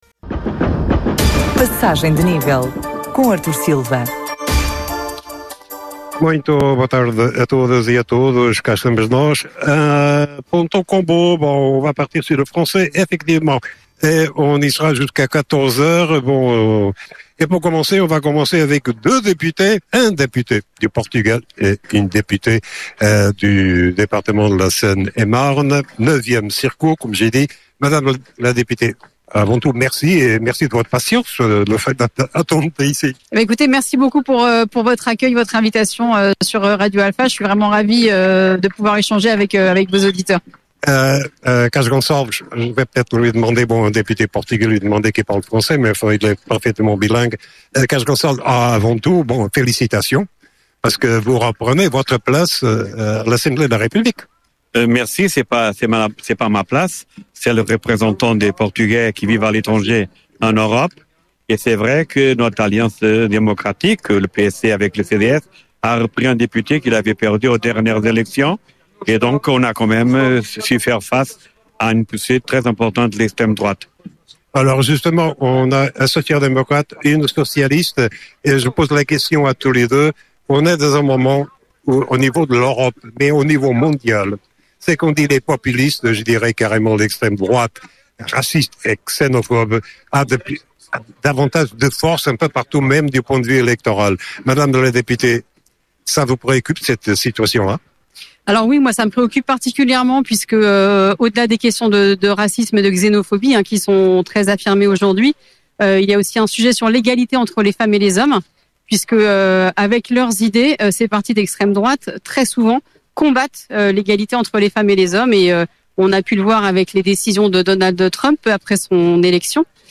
******Esta Semana em direto da Festa de Pontault Combault******